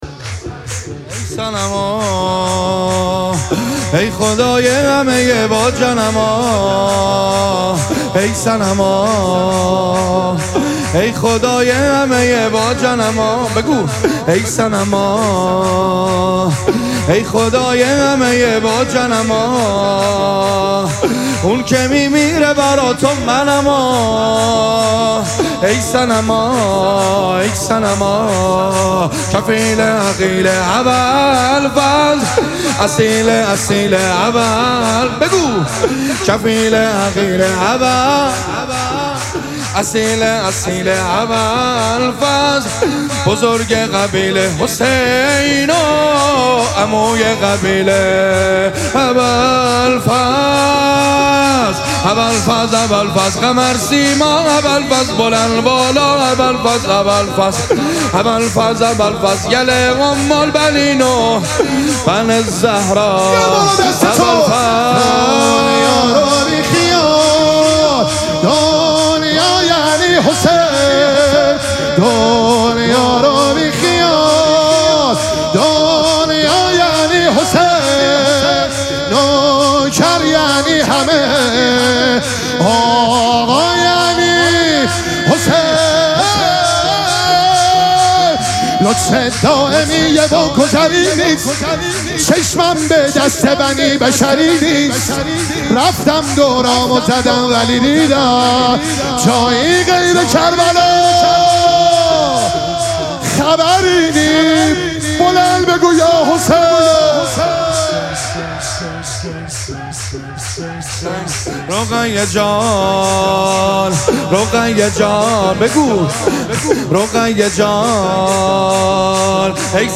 شب دوم مراسم جشن ولادت سرداران کربلا
حسینیه ریحانه الحسین سلام الله علیها
سرود